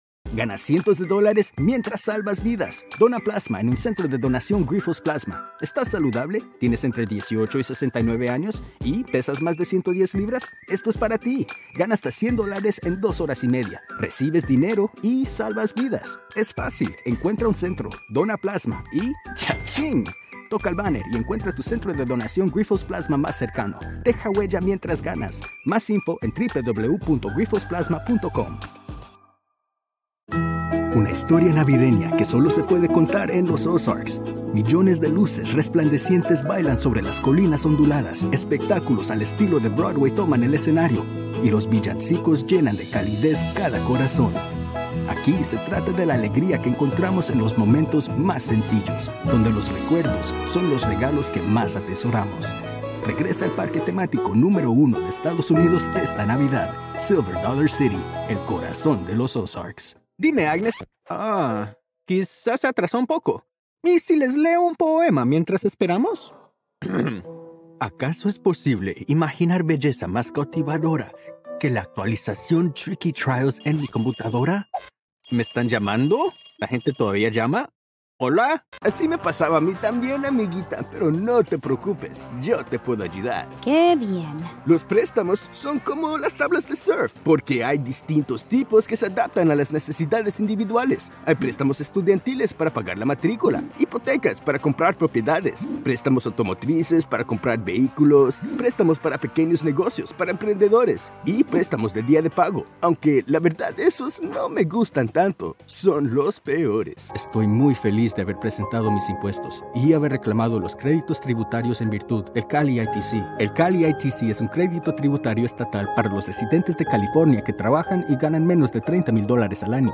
Dynamic, Persuasive, Energetic.
Commercial